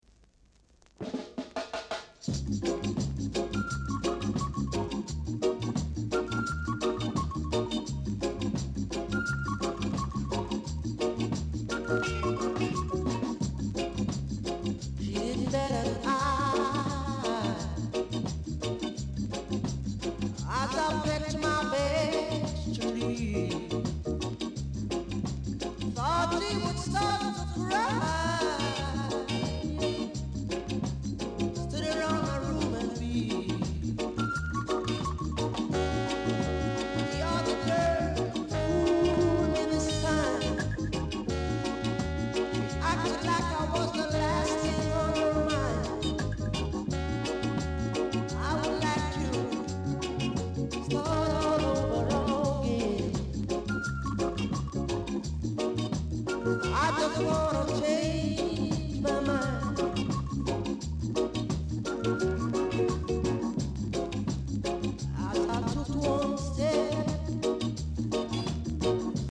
Reggae Male Vocal, Inst